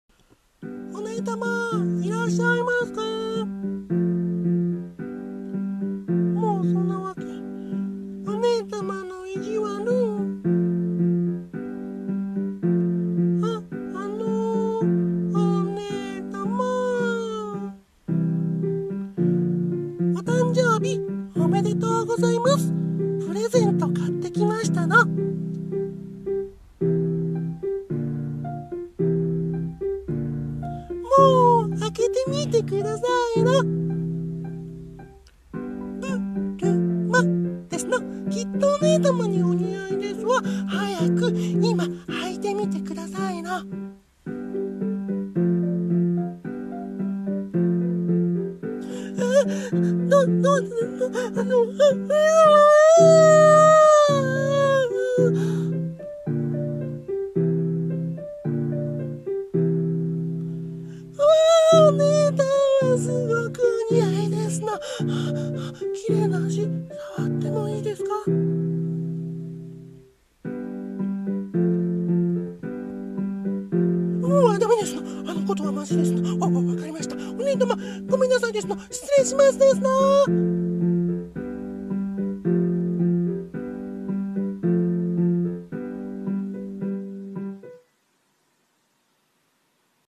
声劇コラボ用】変態妹とツンデレお姉様の日常